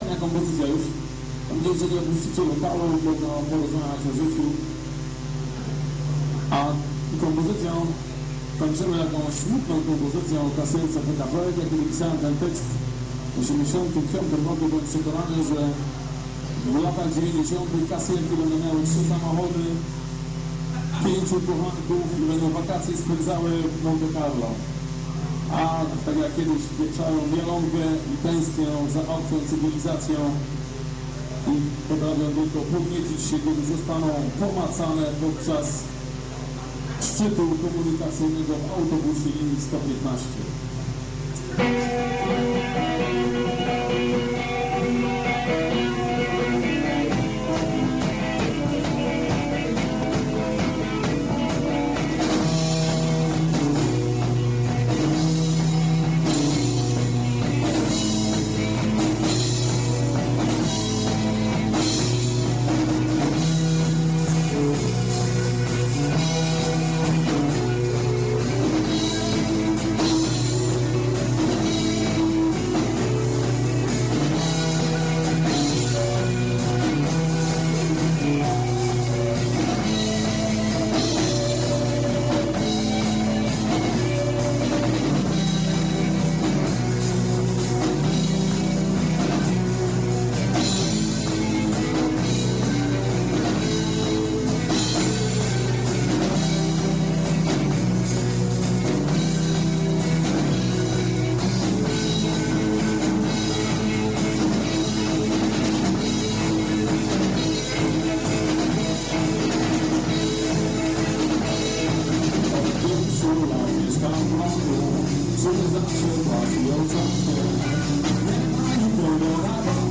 Klub IKS (dawniej Wysepka), 22/12/1999
Jakosc nagran bootlegowa!